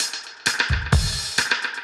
Index of /musicradar/dub-designer-samples/130bpm/Beats
DD_BeatA_130-02.wav